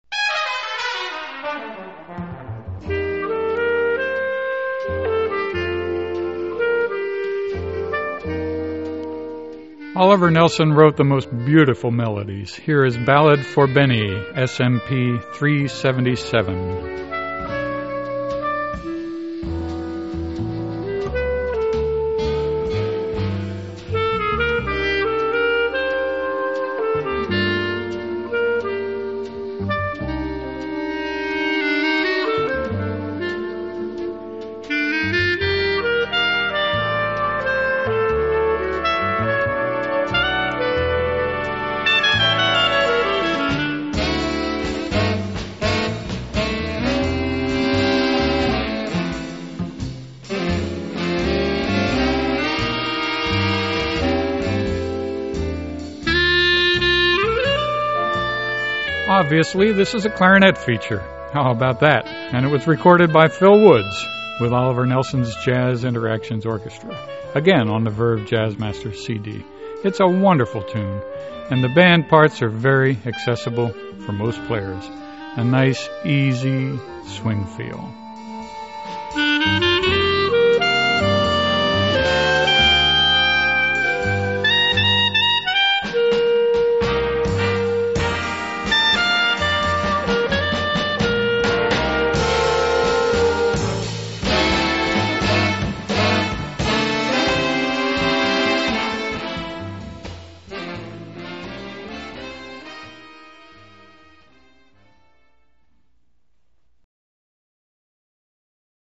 This is a clarinet feature (how about that?)
A nice, easy swing feel. 5-4-4-4 (on Demo CD 109)